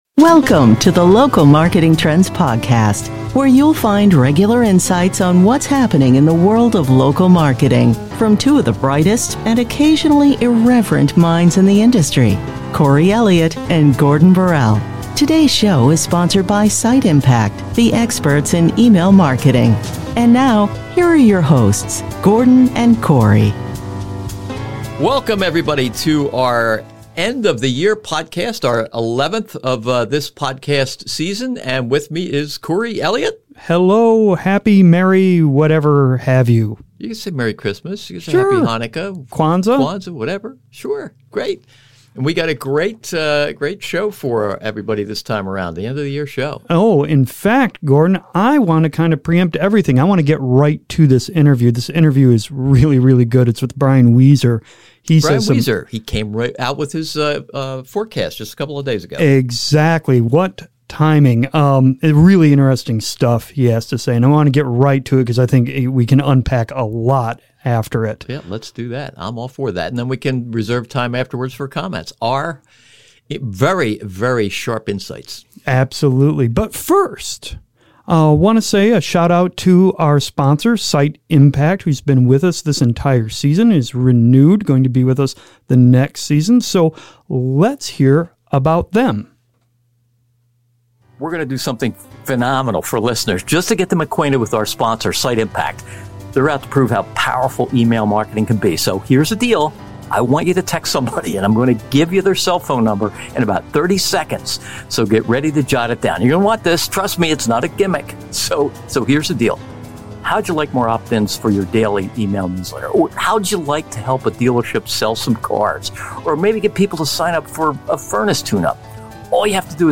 You may be shocked at how he now sees the role of media in the whole marketing equation. The wide-ranging interview